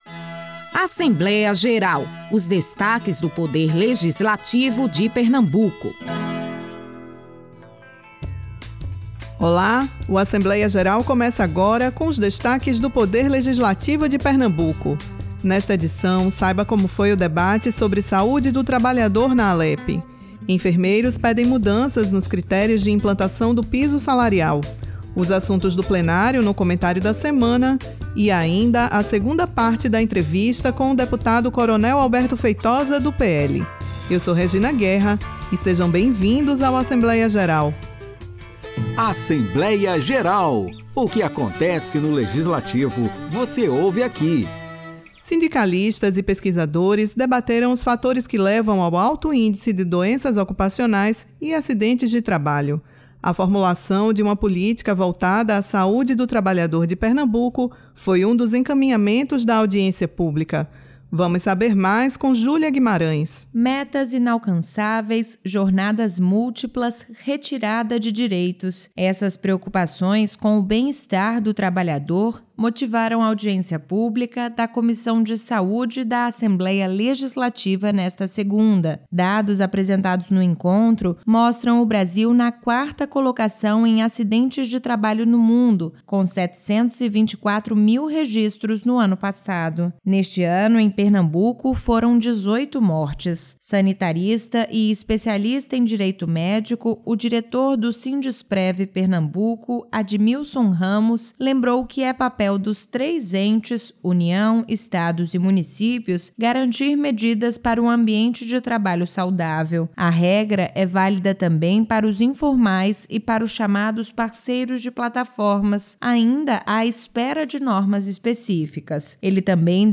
O programa traz também a segunda parte da entrevista com o deputado Coronel Alberto Feitosa (PL), que explica como foi sua trajetória na política. O programa Assembleia Geral é uma produção semanal da Rádio Alepe, com os destaques do Legislativo pernambucano.